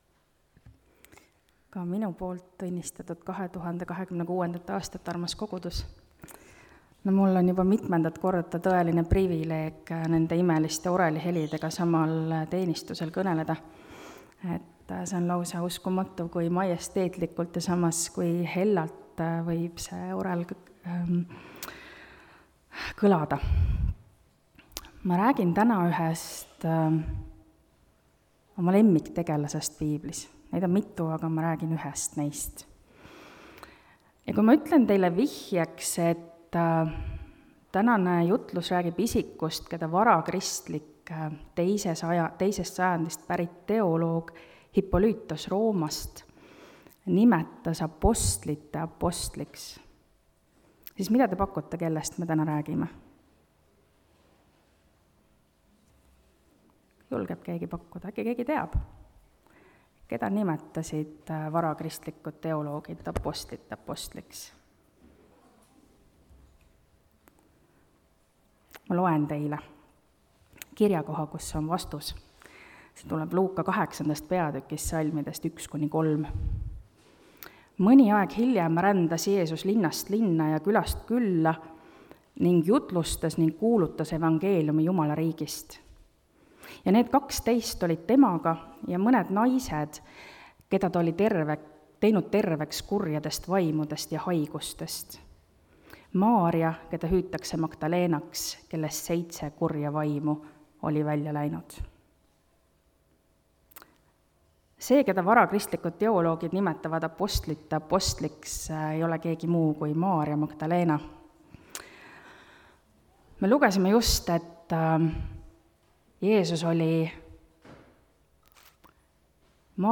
kategooria Audio / Jutlused / Teised